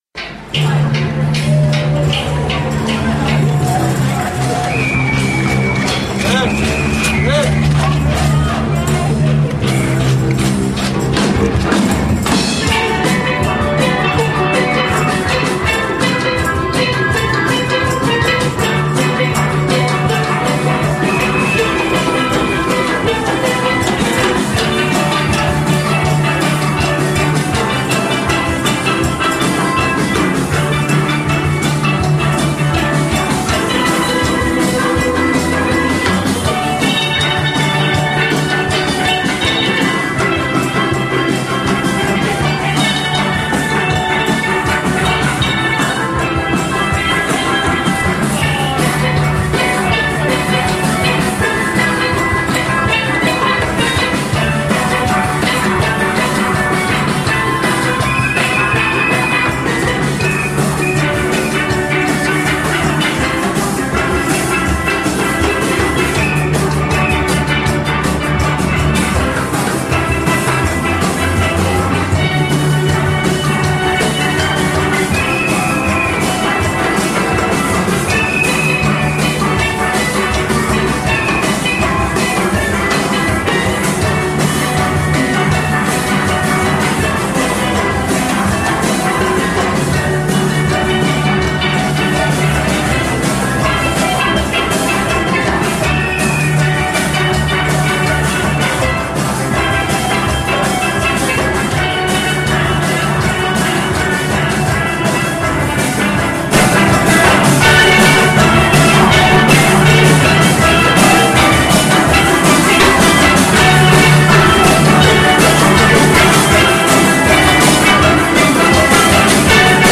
A steel drum band
broadcasts
on the streets of Manchester, England